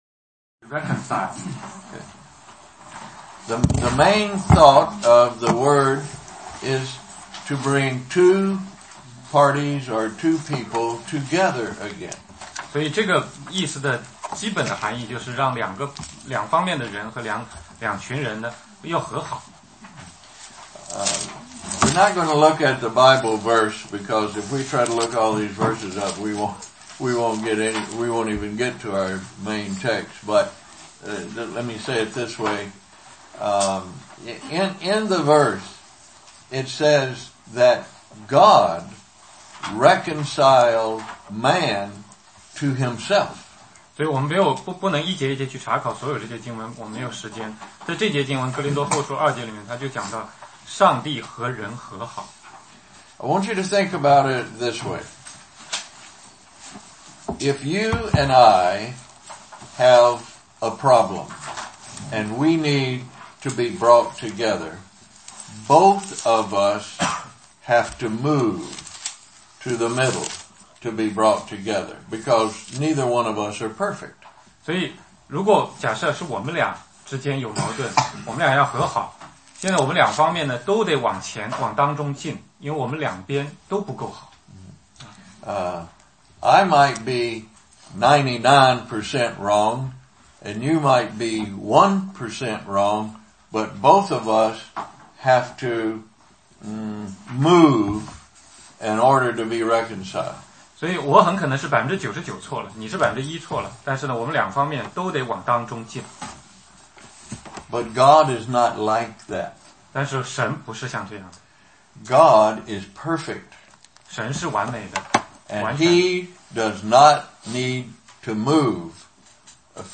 16街讲道录音 - 历代志下 20, 20-21